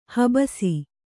♪ habasi